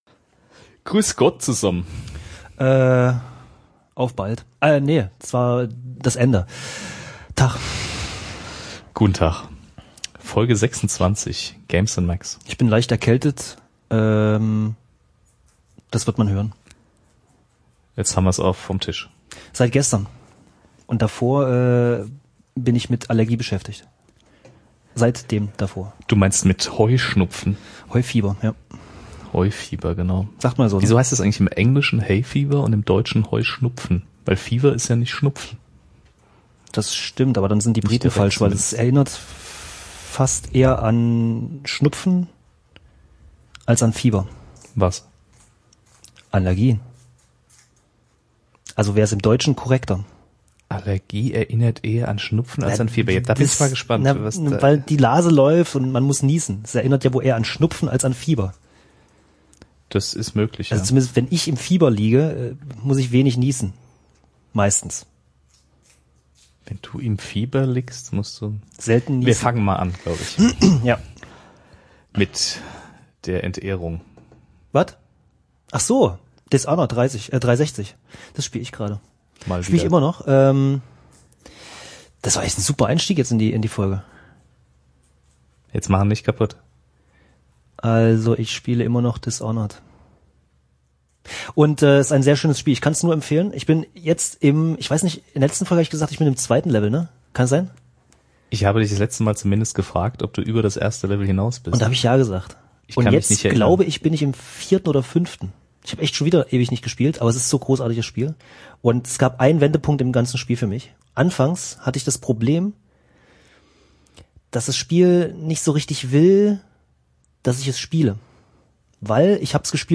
Freut euch auf großartige Audioqualität.